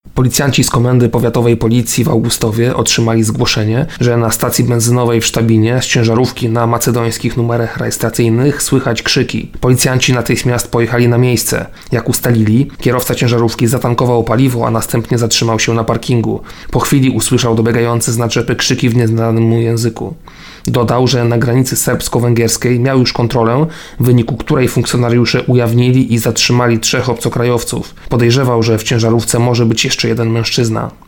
O szczegółach mówi starszy aspirant